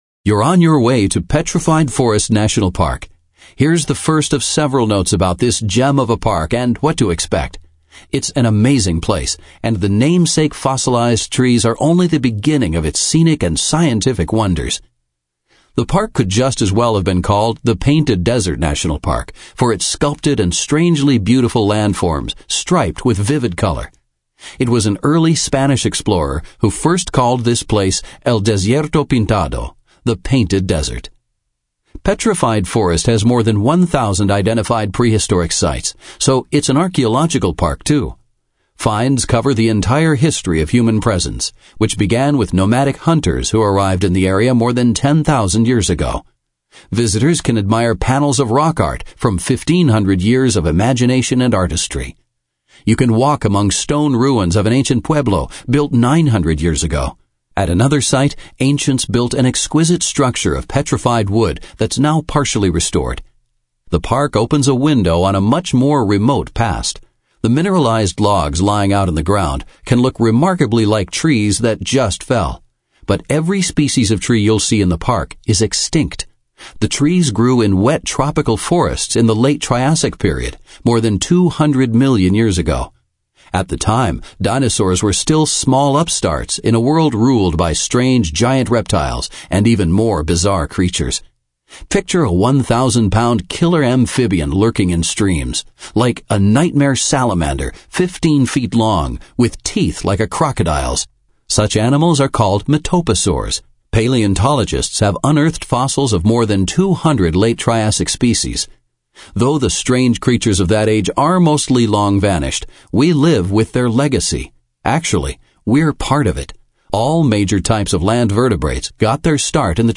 Just Ahead’s GPS-guided audio tour of Petrified Forest National Park is a narrated guide to a small gem of a park and a beautiful slice of northern Arizona.